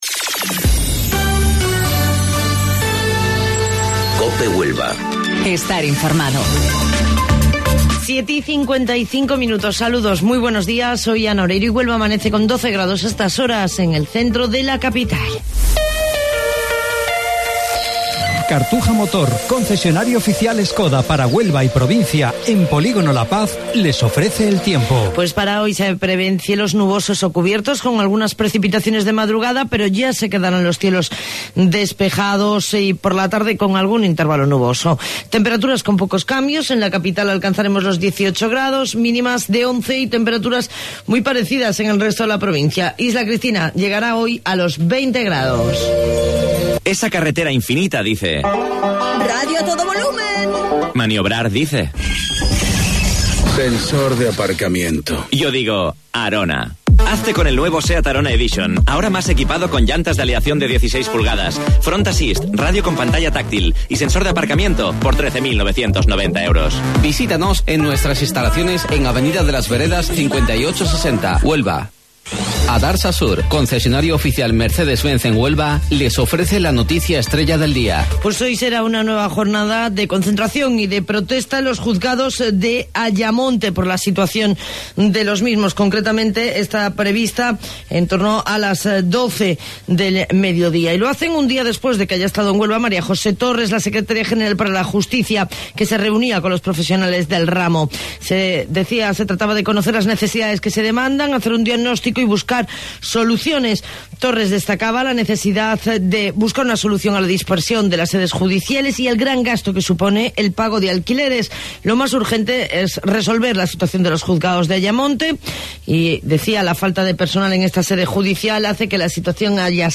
AUDIO: Informativo Local 07:55 del 25 de Abril